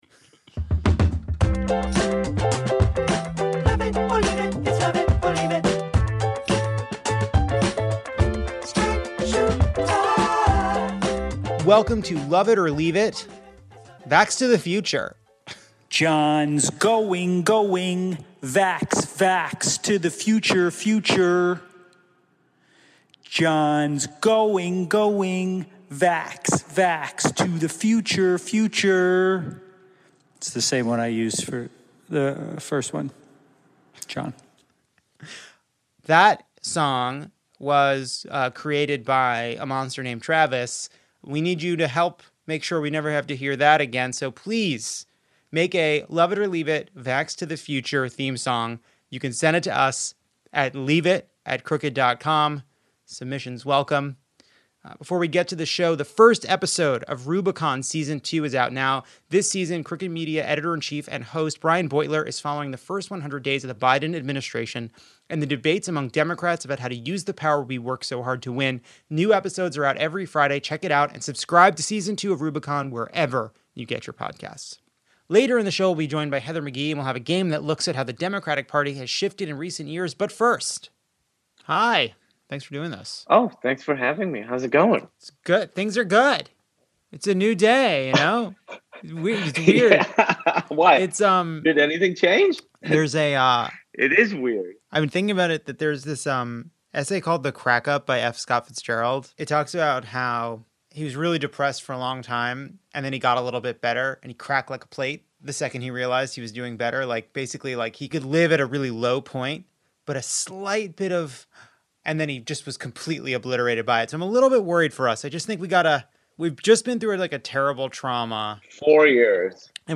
Kumail Nanjiani and I talk through our feelings about this moment. Heather McGhee talks about her book The Sum of Us and how to move forward in a divided country. And we play a game about Democratic moderates and how their views have changed over the years.